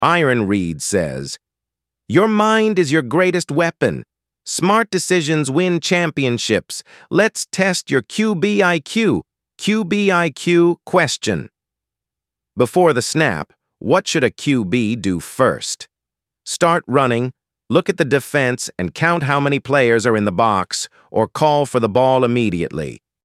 ElevenLabs_2026-01-27T16_32_48_Adam – Dominant, Firm_pre_sp89_s41_sb48_se0_b_m2